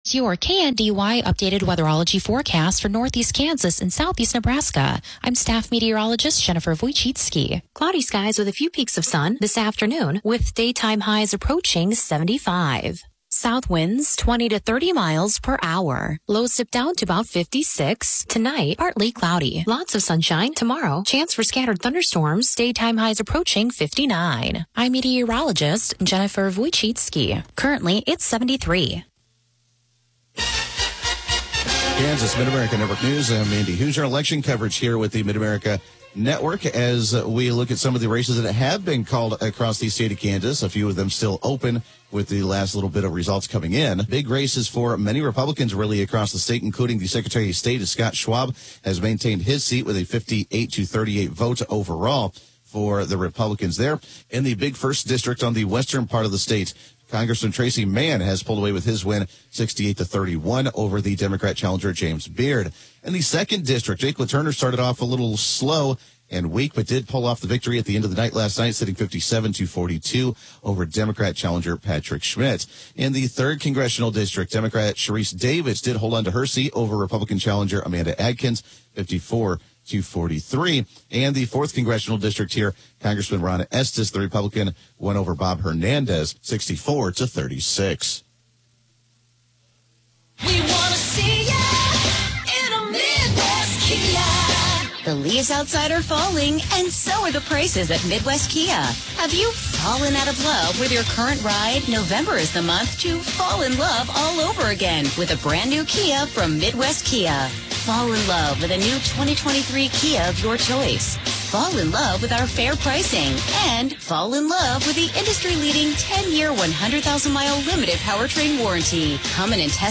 Broadcasts are archived daily as originally broadcast on Classic Country AM 1570/FM 94.1 KNDY.